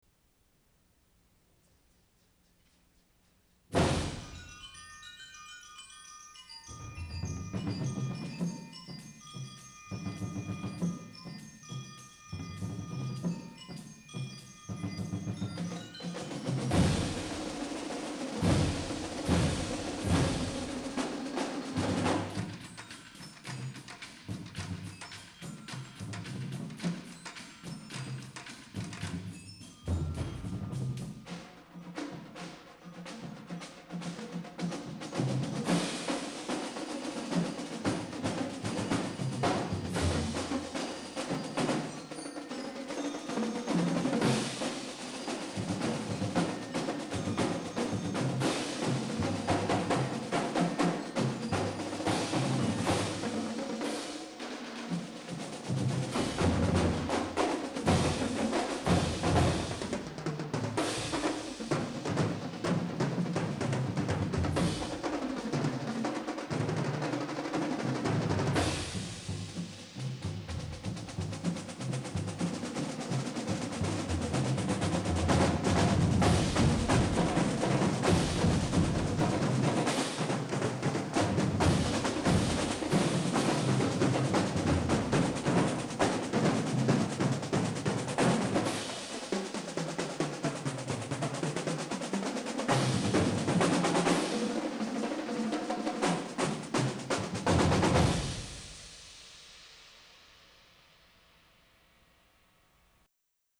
We worked up Pursuit, a solo with two toms in front of each snare player (a series of chromatic pitches down the line), intricately woven into the feature.
musicnotes-tiny“Pursuit” Percussion Solo
WVU Drumline – Pursuit
1987-Percussion-Solo-Pursuit.mp3